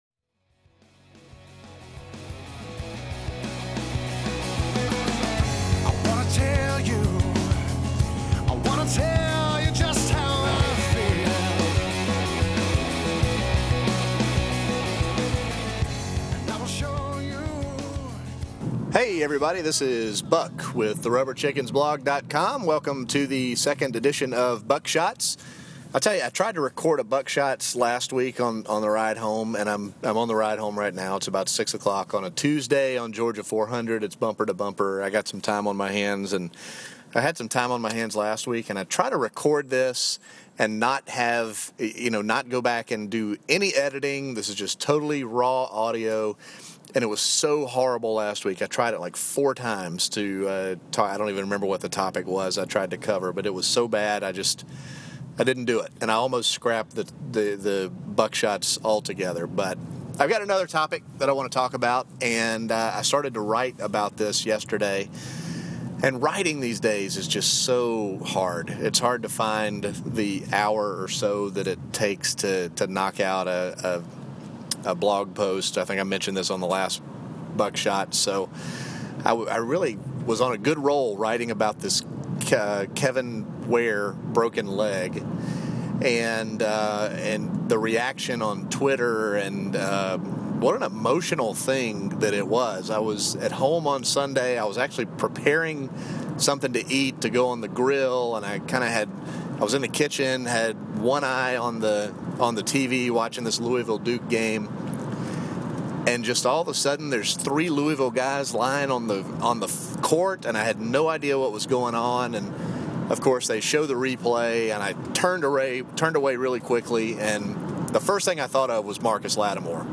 raw audio while headed north on GA 400